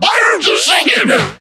mech_mike_lead_vo_01.ogg